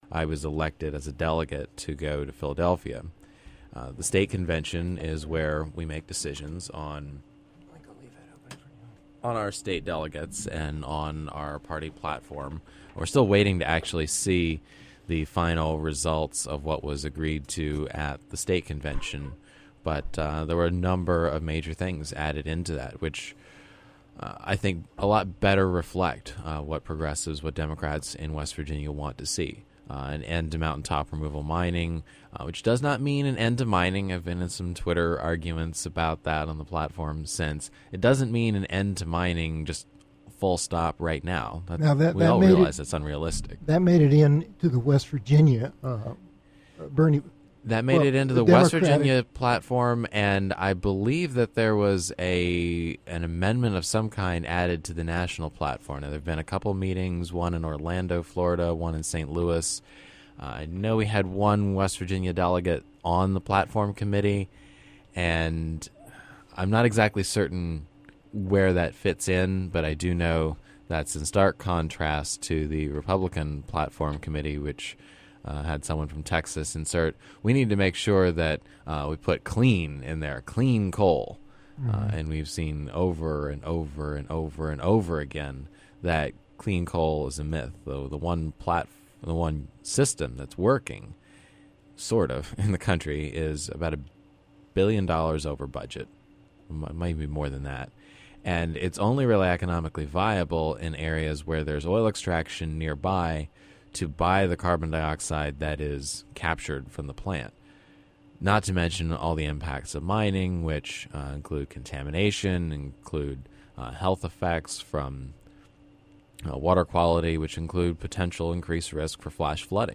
The audio was originally broadcast on WSHC Shepherdstown, from the campus of Shepherd University on August 6, 2016.